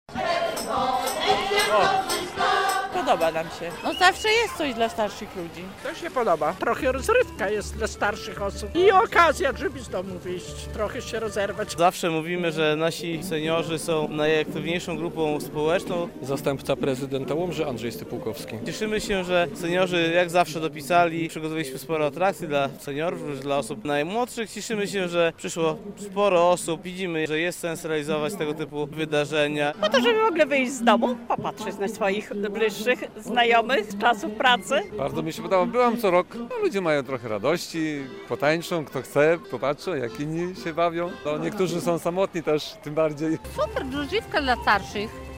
Występy zespołów lokalnych, atrakcje dla dzieci, ale także strefy aktywności i możliwość zrobienia badań. Przez całą niedzielę (15.06) na Starym Rynku w Łomży trwa Seniorada.
I okazja żeby z domu wyjść, trochę się rozerwać - mówili obecni na imprezie seniorzy.
Zastępca prezydenta Łomży Andrzej Stypułkowski podkreślał, ze seniorzy są jedną z najbardziej aktywnych grup w mieście.